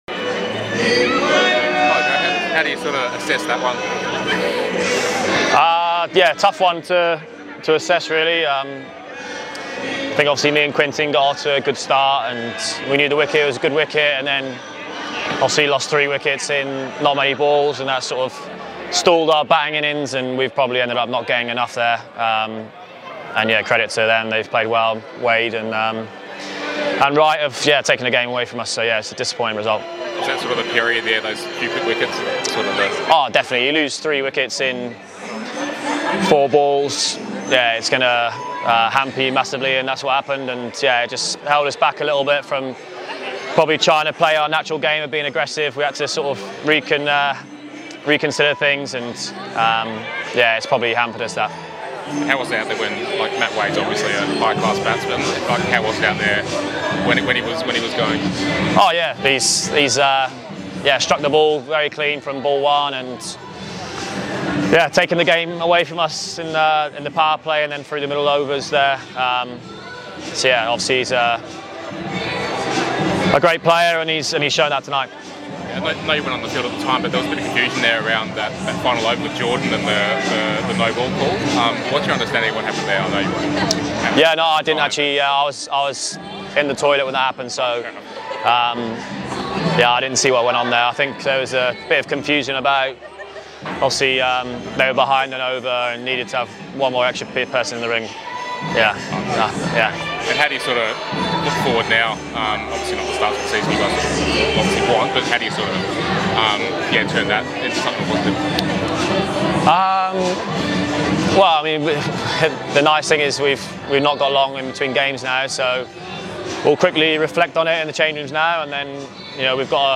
Melbourne Renegades overseas player and opening bat, Joe Clarke (38 off 25) after their loss against the Hobart Hurricanes at Blundstone Arena tonight.